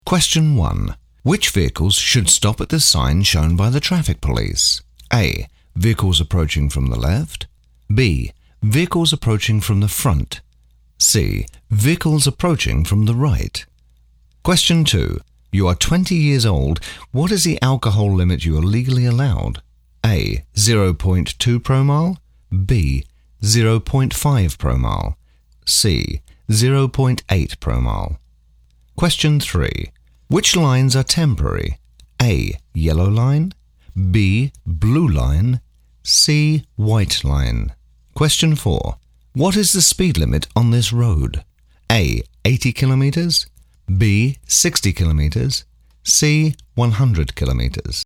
BRITISH ENGLISH DISTINCT ALL ENGLISH ACCENTS, SEXY, SMOOTH,DEEP, INVITING British voice, Classy, Conversational, informative, interesting, Commanding, Believable, Smooth, hard sell.
Sprechprobe: eLearning (Muttersprache):